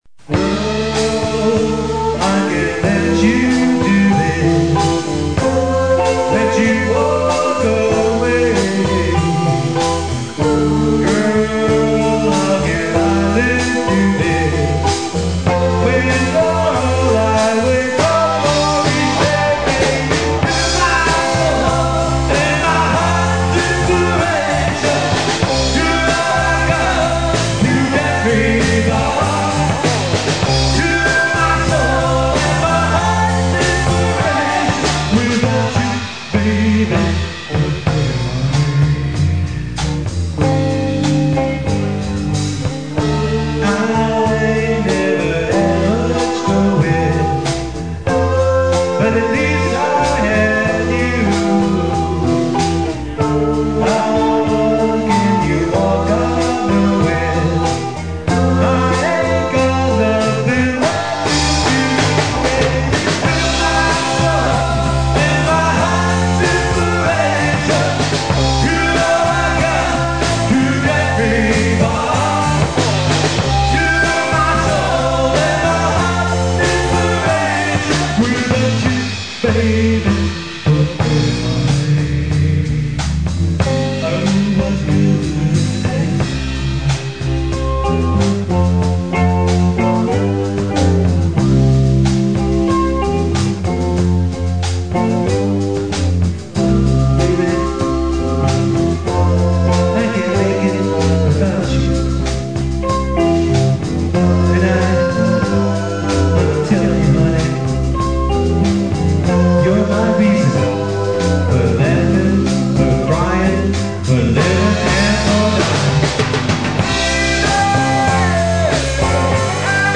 CLASSIC ROCK